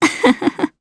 Valance-Vox_Happy2_jp.wav